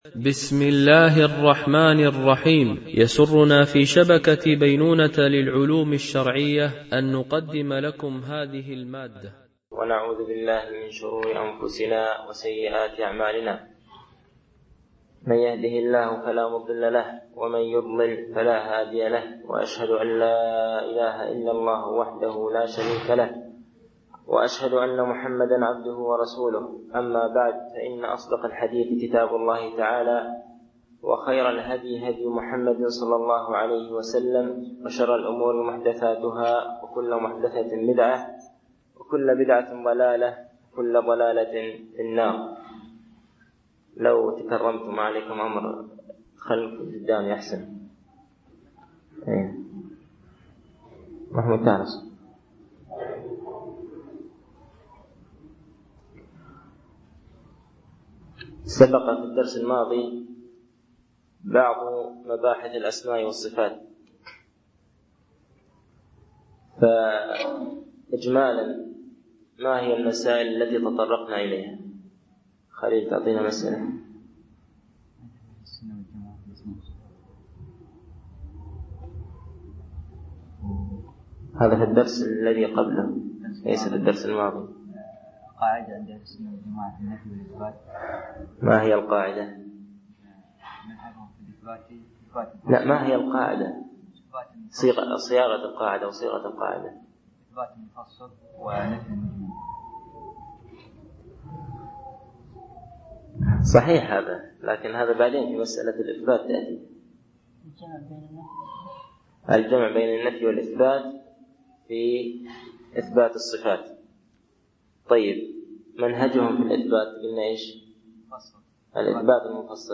شرح أعلام السنة المنشورة ـ الدرس 21 ما هو توحيد الأسماء والصفات ؟ (2)